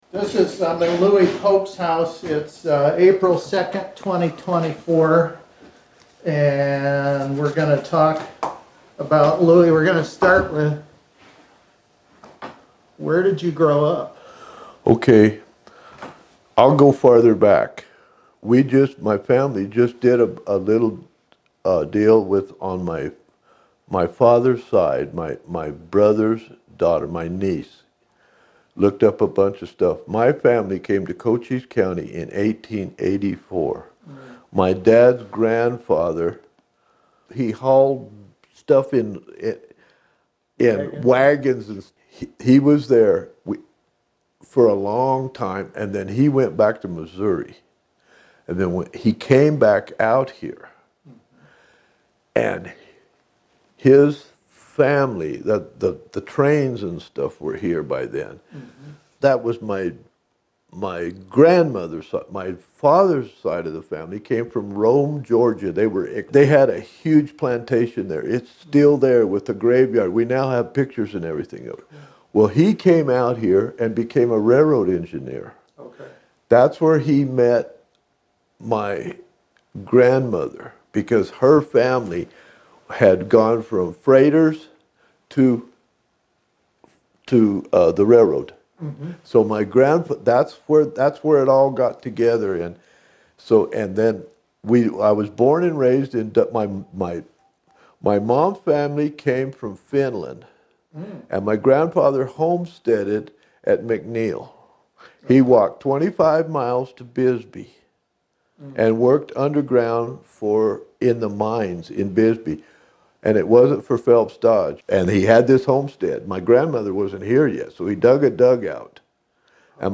As a part of this project, our community has begun collecting oral histories in both audio and video format.
These include both full interviews (in their entirety) and shorter excerpts addressing common topics.